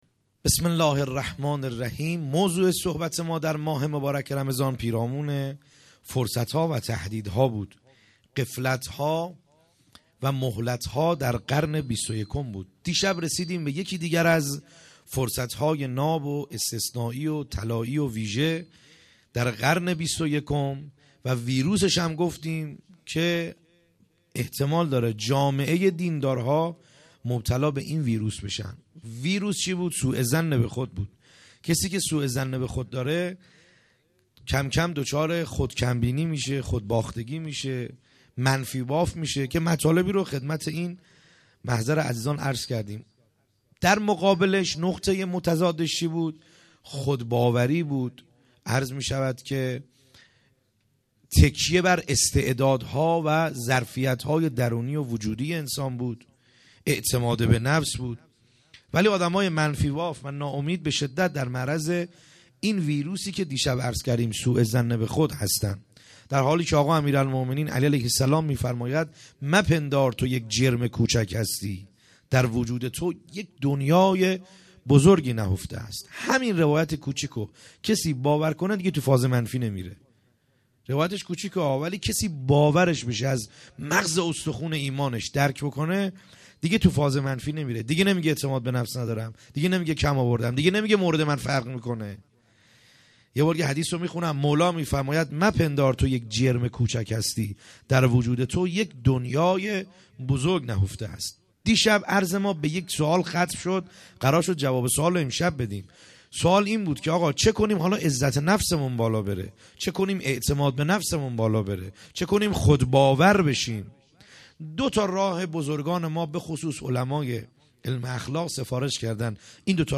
خیمه گاه - بیرق معظم محبین حضرت صاحب الزمان(عج) - سخنرانی | شب هفتم